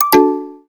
Deactivate.wav